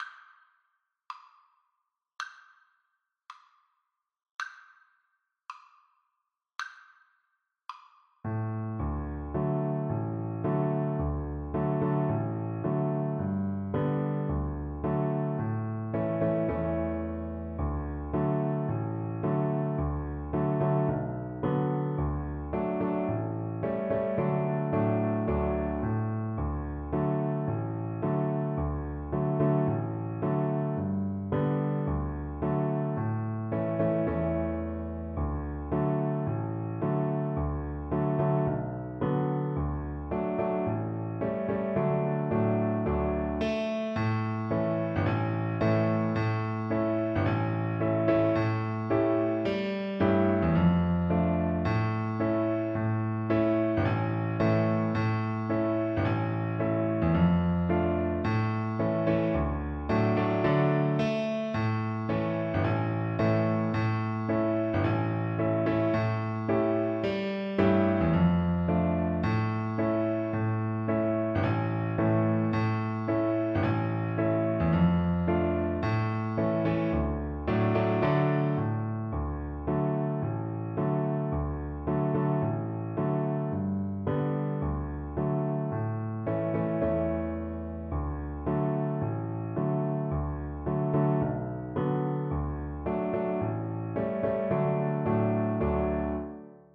Cello
2/4 (View more 2/4 Music)
F#3-E5
D major (Sounding Pitch) (View more D major Music for Cello )
Molto Moderato = c. 84
Traditional (View more Traditional Cello Music)